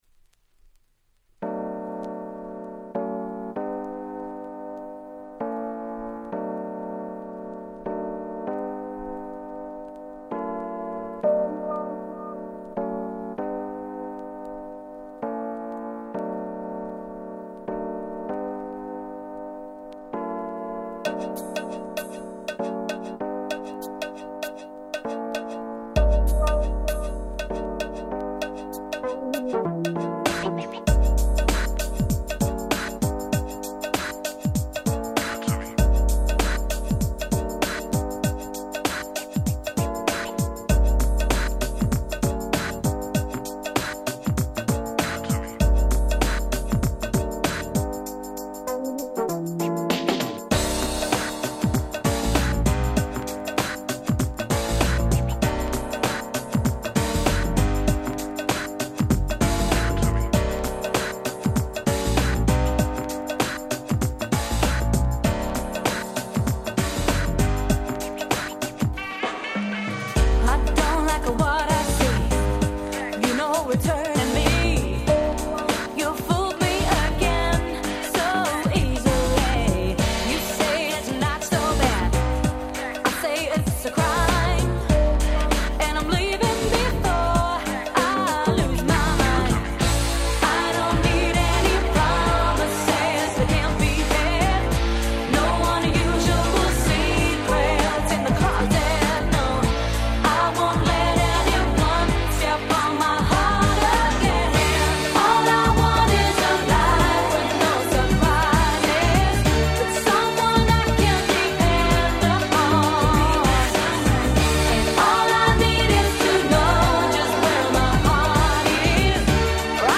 95' Nice UK R&B !!
詳細不明の白人のおねーちゃん2人組。
Ground Beat調の軽快なBeatに華やかな歌が乗る大変キャッチーな1曲。
キャッチー系 グランド グラウンドビート Grand